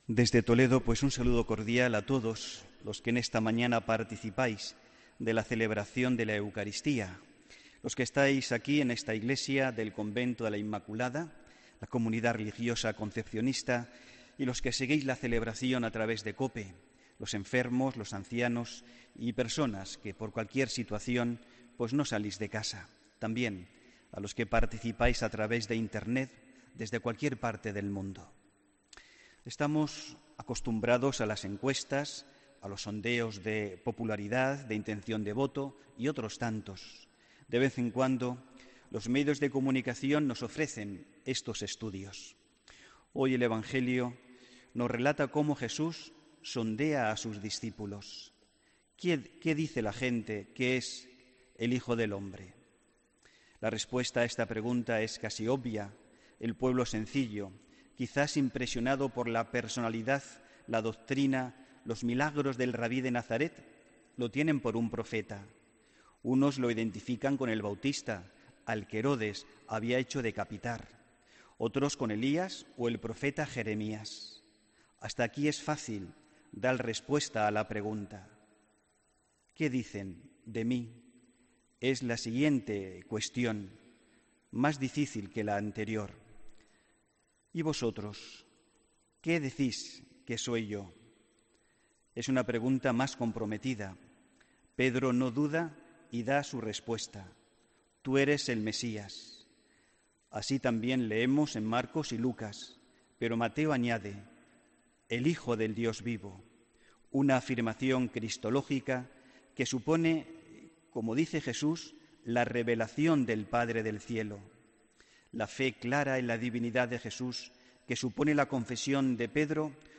Homilía del domingo 27 de agosto de 2017